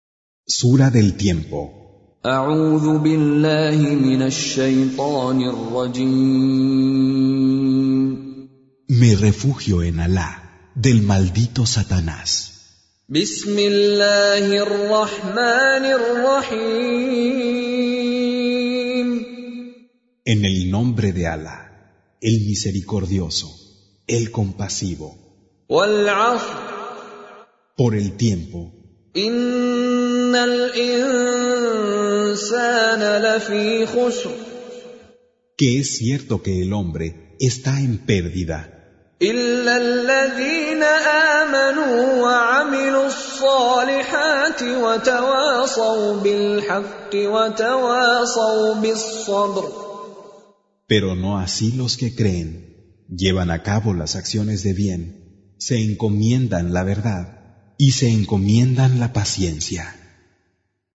Con Reciter Mishary Alafasi
Surah Sequence تتابع السورة Download Surah حمّل السورة Reciting Mutarjamah Translation Audio for 103. Surah Al-'Asr سورة العصر N.B *Surah Includes Al-Basmalah Reciters Sequents تتابع التلاوات Reciters Repeats تكرار التلاوات